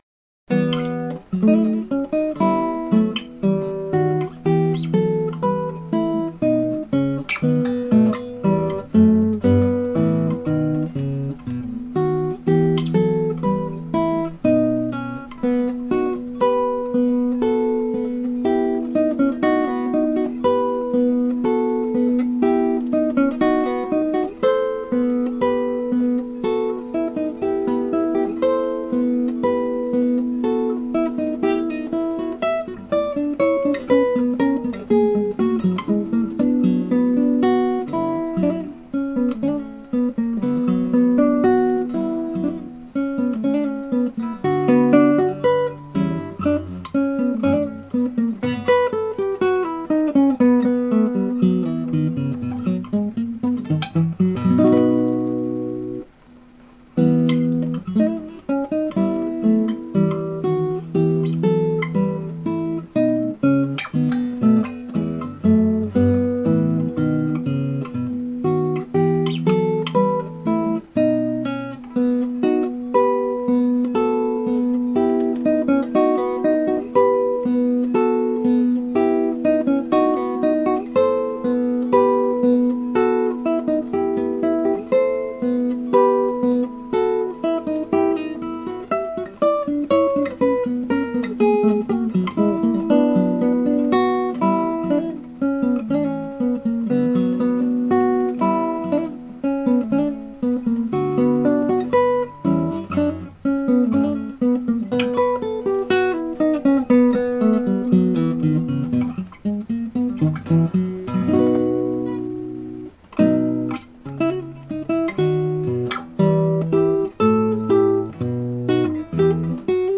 D.スカルラッティ : ソナタ ホ短調 K.11(L.352) (アマチュアギター演奏)
(アマチュアのクラシックギター演奏です [Guitar amatuer play] )
ここで録音した私の演奏ではこの部分の最初の32分音符が16分音符になっていてその後の付点が無くなっていますが・・・・。
私の演奏は、相変わらずへたっぴーです。
少し弾き込んで再録音しました。まだ不満です。
運指はインテンポで弾くことを優先してかなり変更しています。
scarlatti_sonata_k11_d.mp3